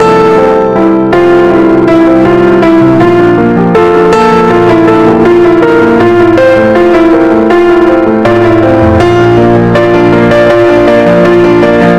Ein Limiter ist nichts anderes als ein extrem stark eingestellter Kompressor. Es ist deutlich zu hören, dass das Piano lauter geworden ist. Der Klang hat aber auch deutlich gelitten. So kann man die einzelnen Anschläge schwerer ausmachen, als bei der originalen Version.
Delicate Piano 11-OverCompressed.mp3